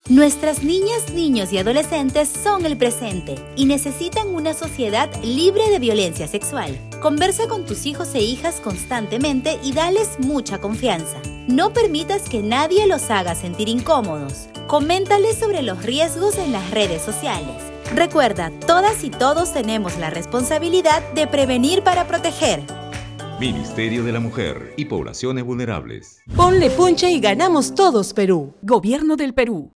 Spot-audio-Prevenir-para-proteger.wav